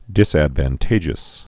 (dĭs-ădvən-tājəs, dĭsăd-vən-)